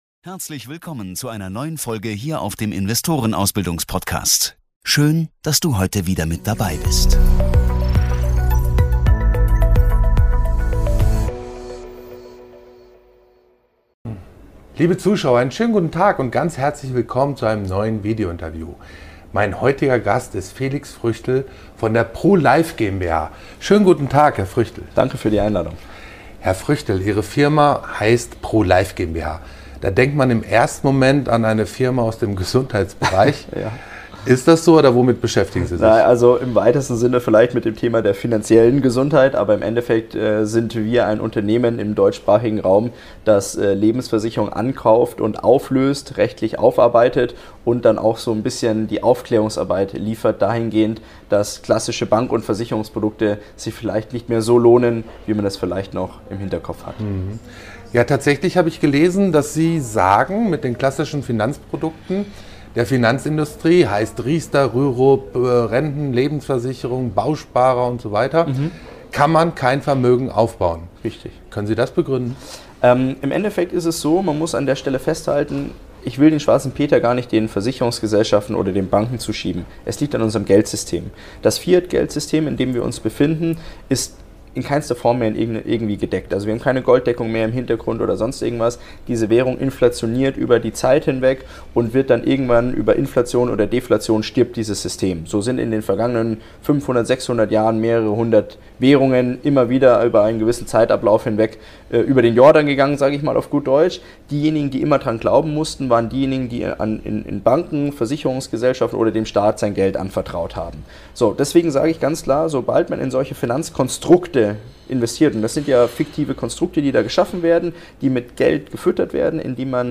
Ein aufrüttelndes Interview über Vermögensschutz in unsicheren Zeiten und warum Gold & Sachwerte wichtiger werden denn je.